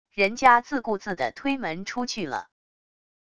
人家自顾自的推门出去了wav音频生成系统WAV Audio Player